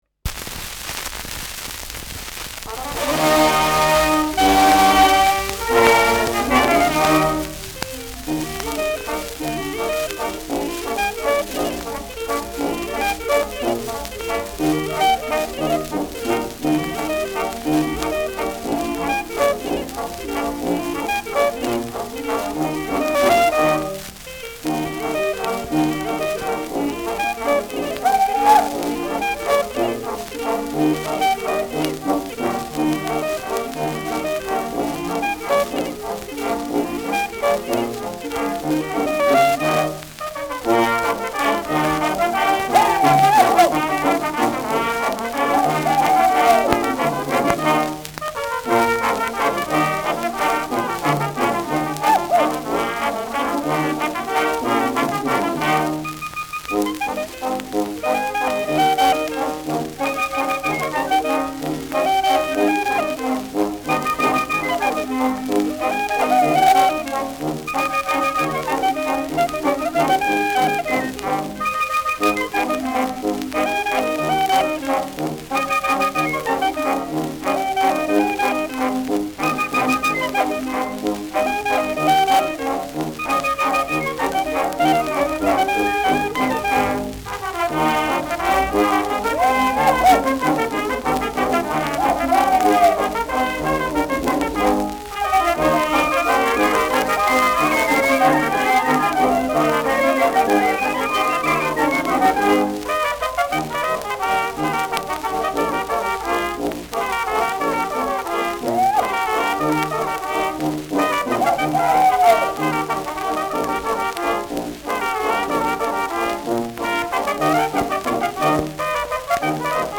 Schellackplatte
Tonrille: graue Rillen : leichte Kratzer
präsentes Rauschen : präsentes Knistern : abgespielt : leichtes Leiern : Nadelgeräusch
Kapelle Lang, Nürnberg (Interpretation)
[Nürnberg] (Aufnahmeort)